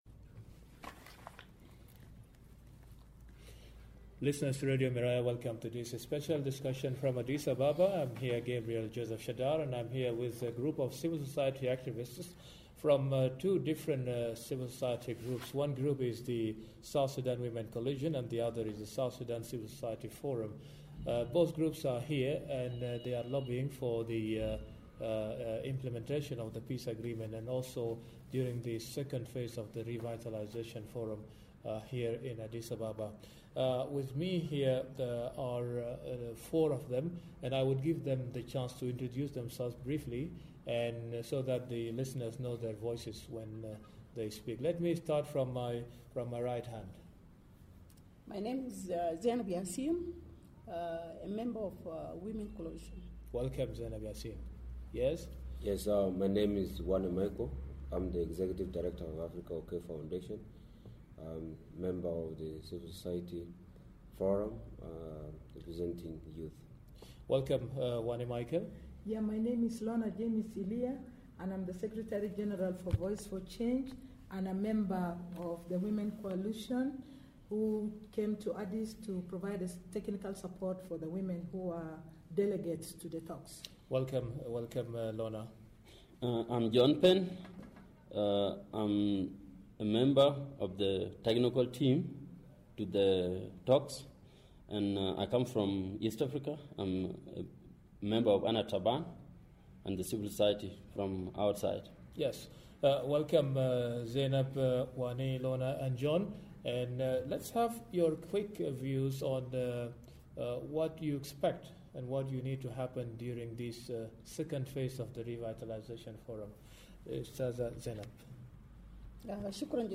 Members of South Sudan Women Civil Society group discuss the prospects of Peace as the High Level Revitalization Forum in Addis Ababa continues.